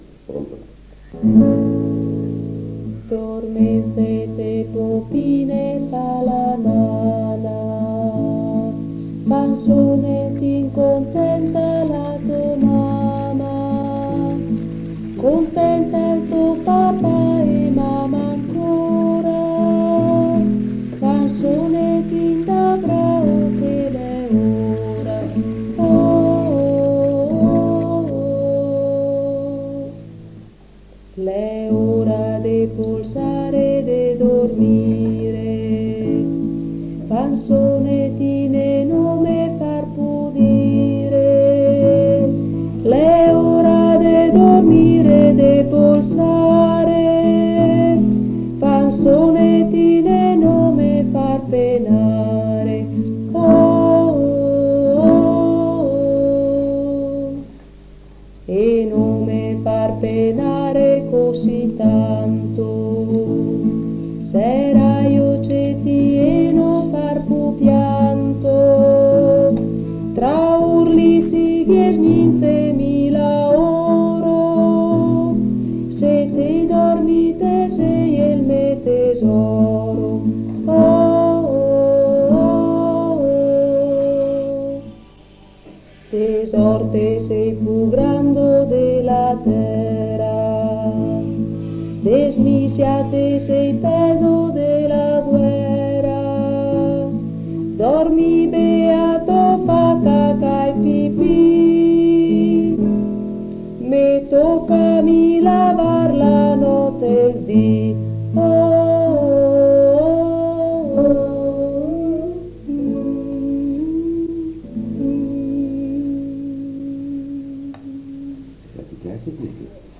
Registrazioni di canti popolari